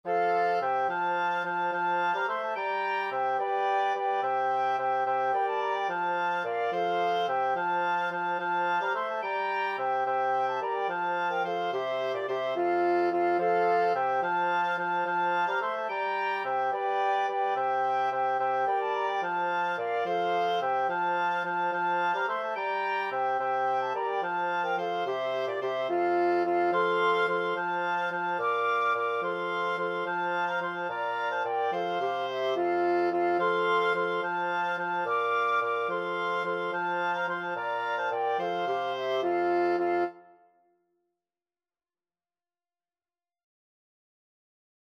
Free Sheet music for Wind Quartet
FluteOboeClarinetBassoon
C major (Sounding Pitch) (View more C major Music for Wind Quartet )
6/4 (View more 6/4 Music)
Wind Quartet  (View more Intermediate Wind Quartet Music)
Classical (View more Classical Wind Quartet Music)